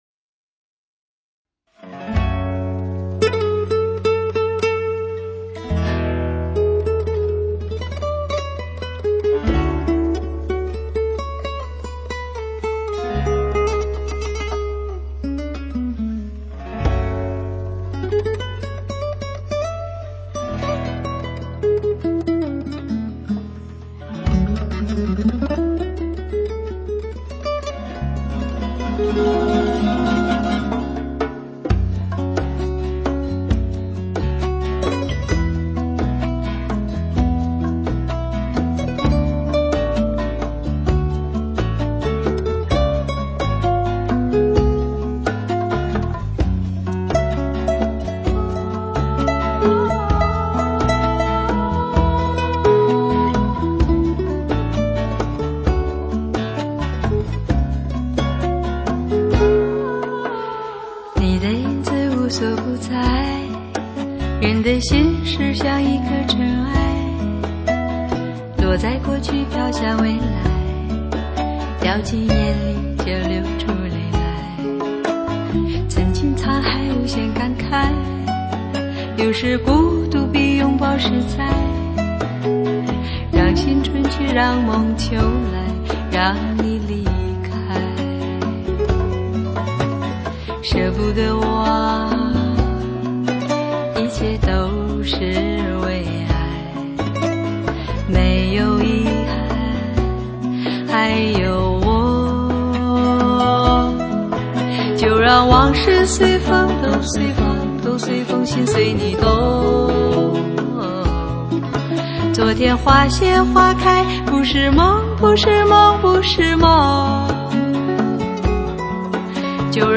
长笛
贝司
钢琴
打击乐
吉他
萨克斯 单簧管
小提琴
中提琴
大提琴
低音提琴
口琴
◆ 真发烧品质 首首主打 没有MIDI 没有浮华 唯有感动……
◆ 超高清晰录音还原音乐真我本色 人声活现 乐器质感触手可及 现场感十足